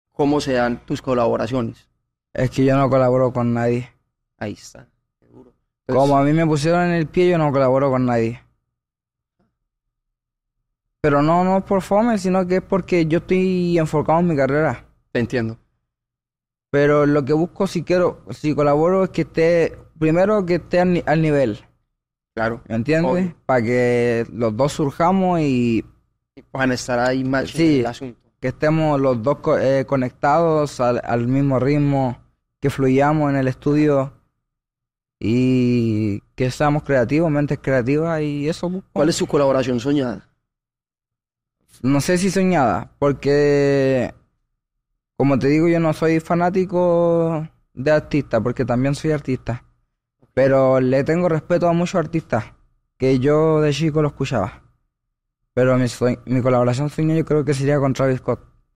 FloyyMenor sin filtros En entrevista sound effects free download